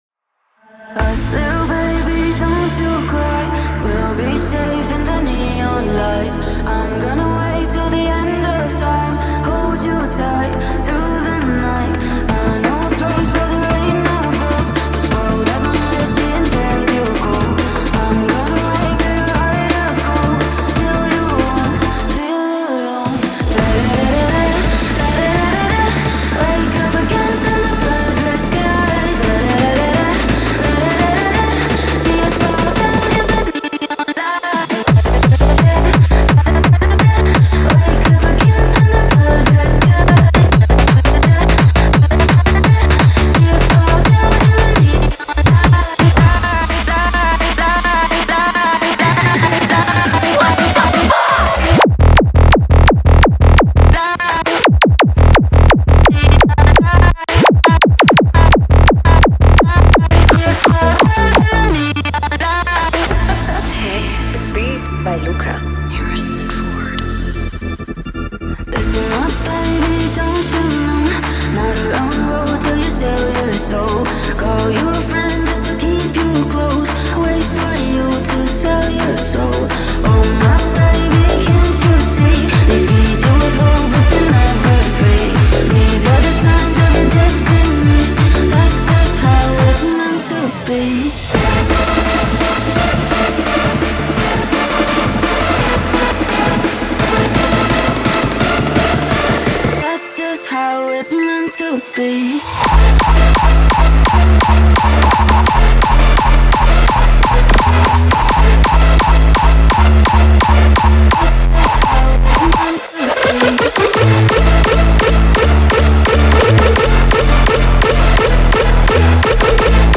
• Жанр: Electronic, Hardstyle